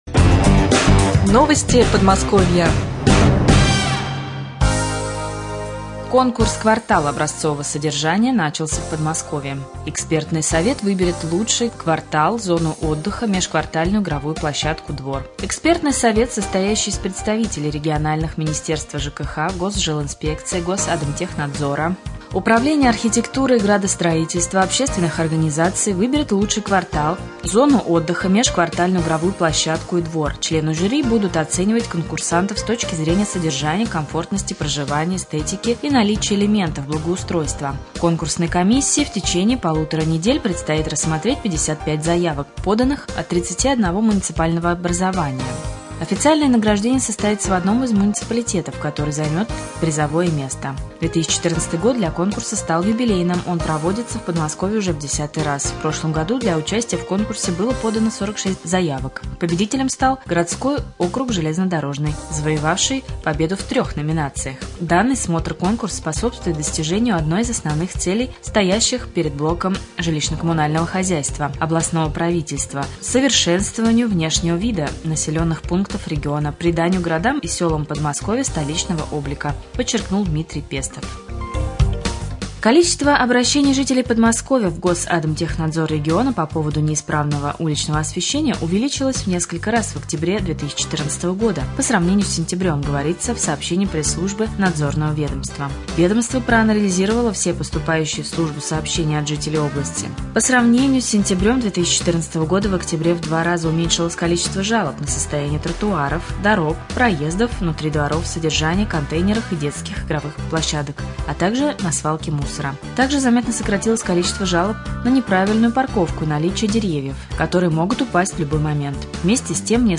13.11.2014г. в эфире Раменского радио - РамМедиа - Раменский муниципальный округ - Раменское